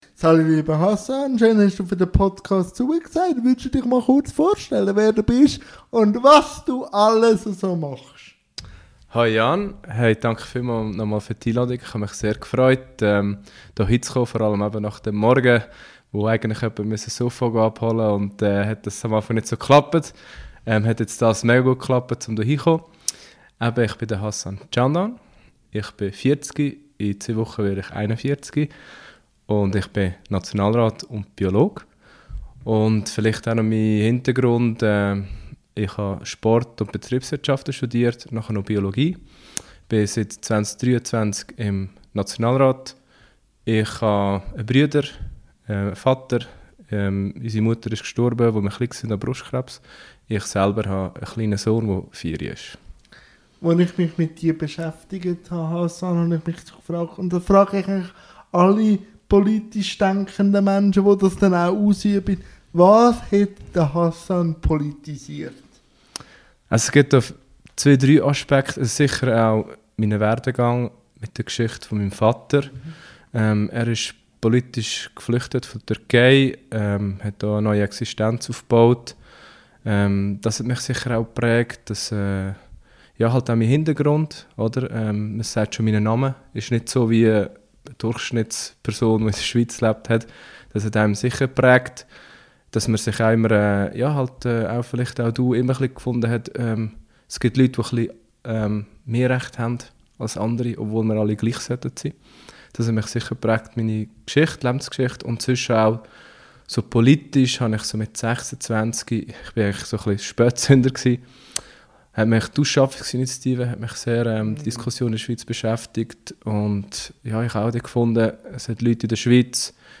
INTERVIEW-THEMEN | Politik, Sport u. v. m